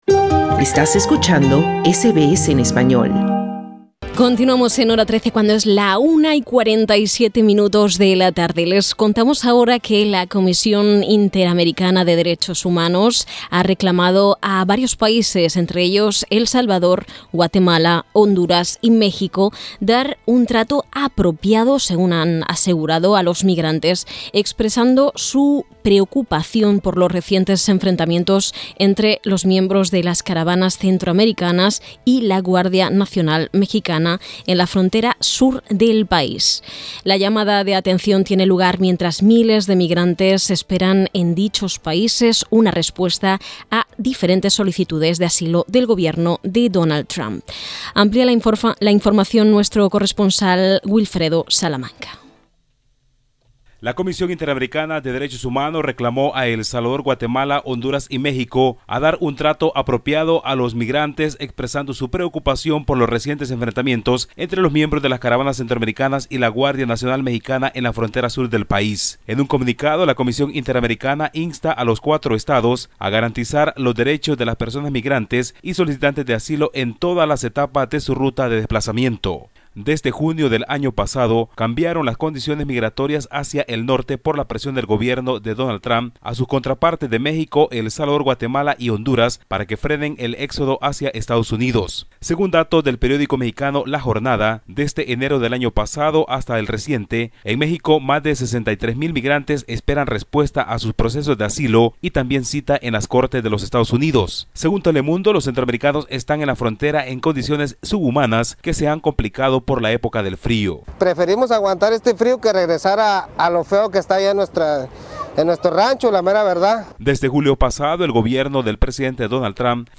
Escucha el reporte del corresponsal en El Salvador